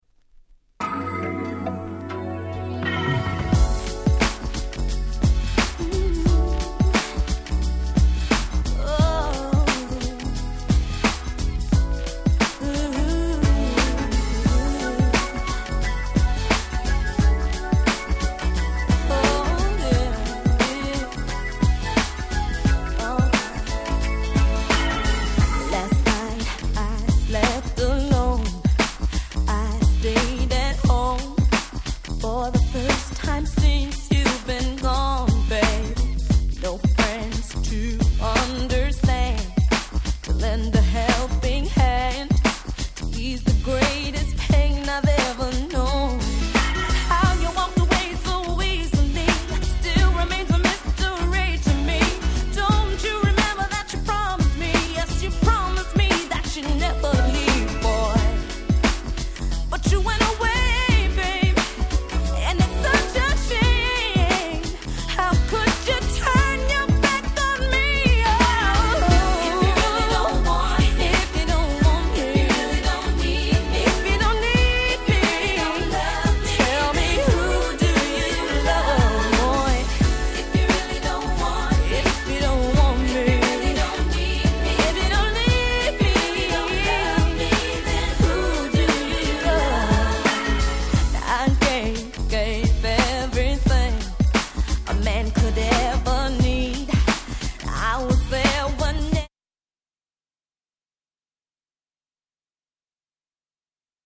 Nice Smooth Mid♪